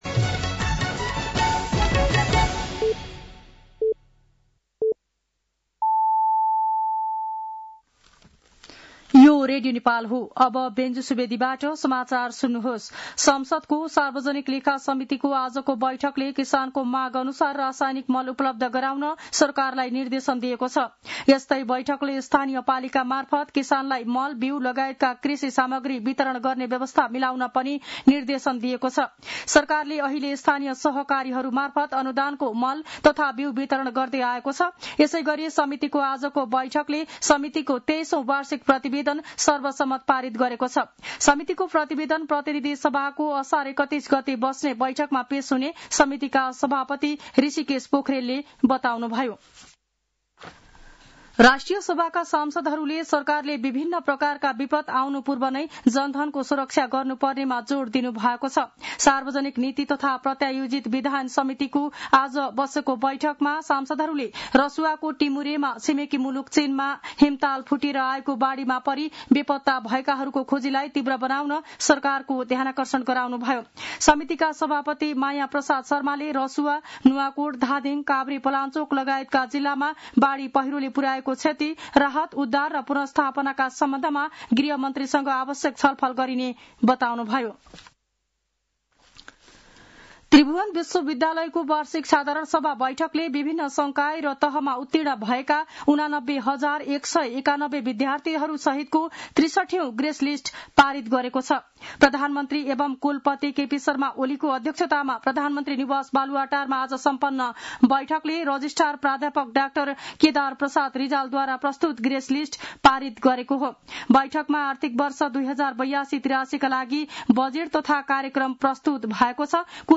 साँझ ५ बजेको नेपाली समाचार : २६ असार , २०८२
5-pm-nepali-news-3-26.mp3